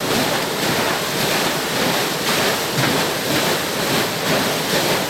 Water Wheel Churn Loop